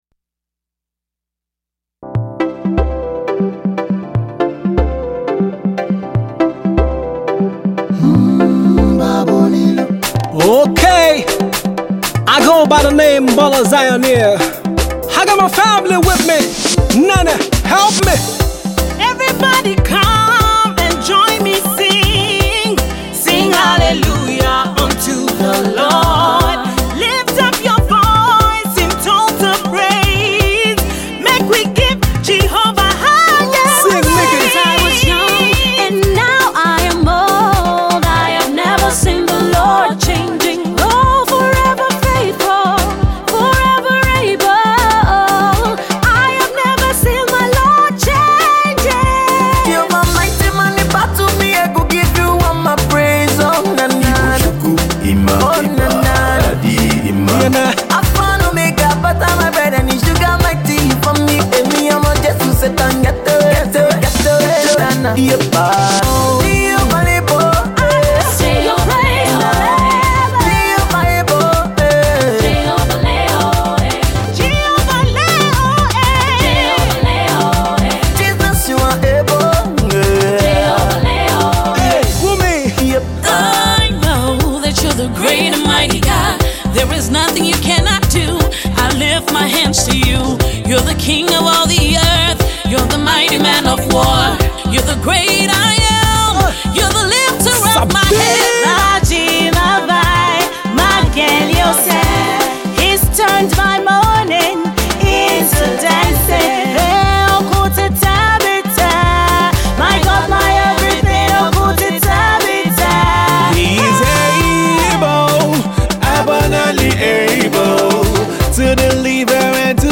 Nigerian Gospel music